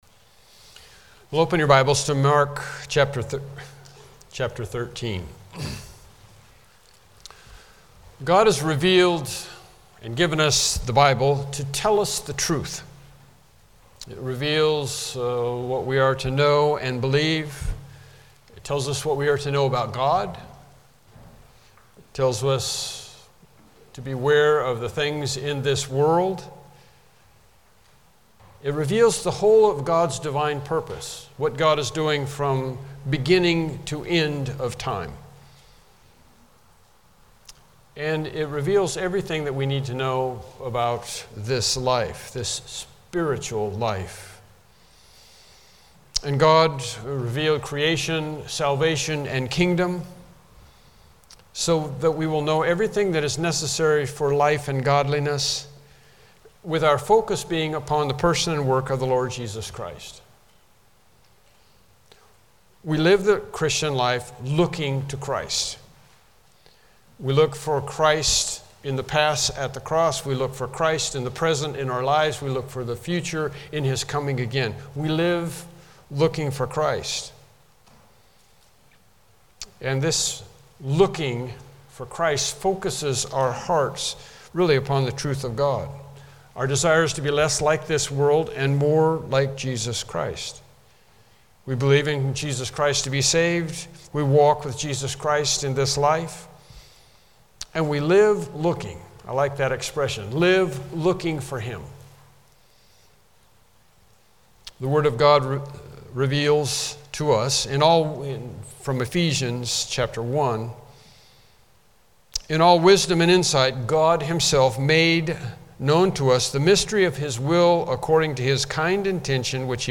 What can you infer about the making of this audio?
TBD Service Type: Morning Worship Service « Lesson 3